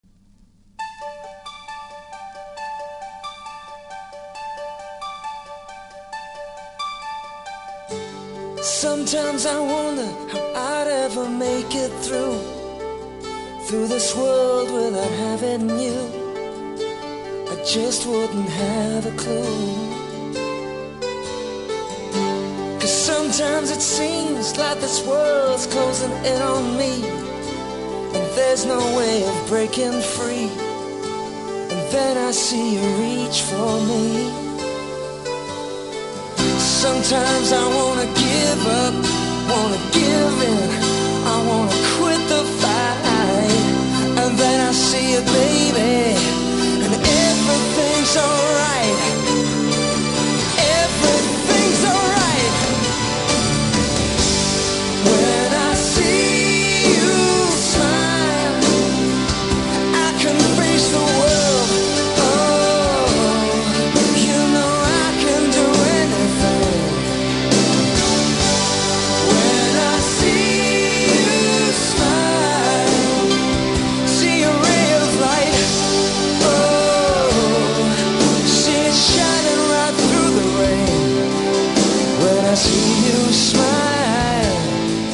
80'S MALE GROUP